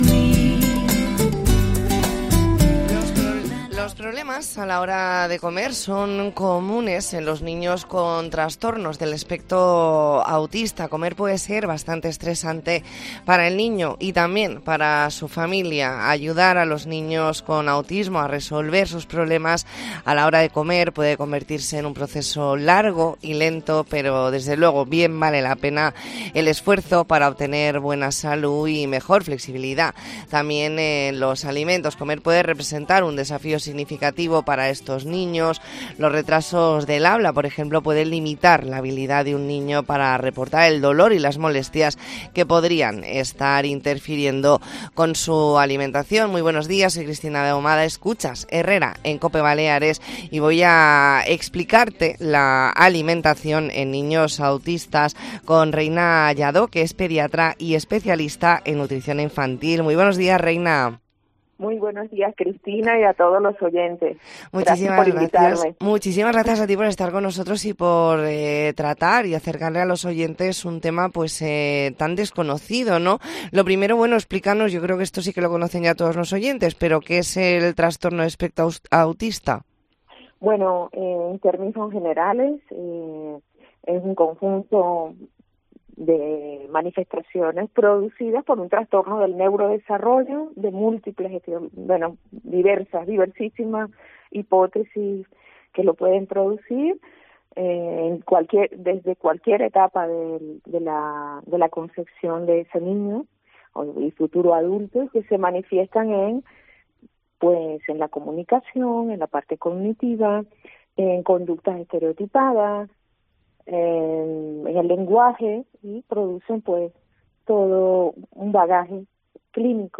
Entrevista en Herrera en COPE Mallorca, martes 12 de marzo de 2024.